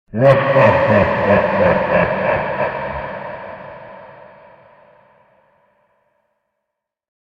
Dark Laugh Sound Effect
Description: Dark laugh sound effect. Deep male voice laugh with eerie reverb creates a dark and chilling effect. Perfect for horror films, thrillers, haunted scenes, and spooky games, this sinister sound adds instant tension and fear.
Dark-laugh-sound-effect.mp3